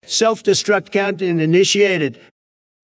self-destruct-countdown.wav